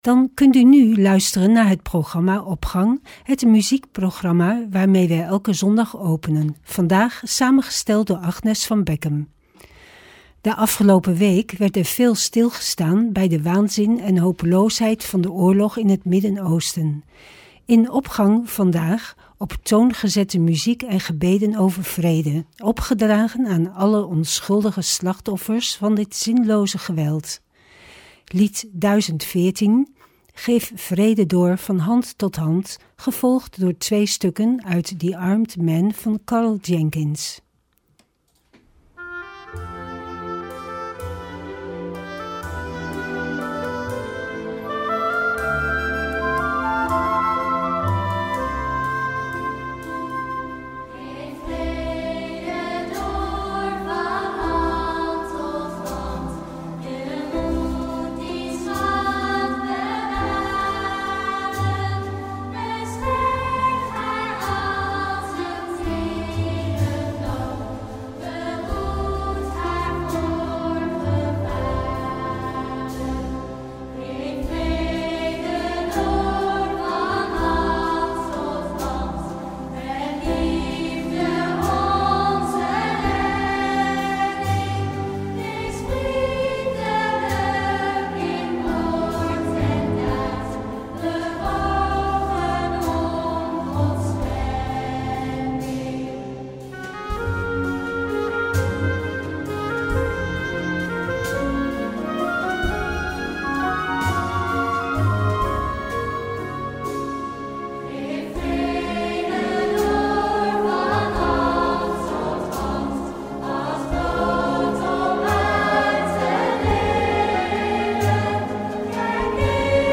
Opening van deze zondag met muziek, rechtstreeks vanuit onze studio.
In de Opgang vandaag op toon gezette muziek en gebeden over vrede, opgedragen aan alle onschuldige slachtoffers van dit zinloze geweld.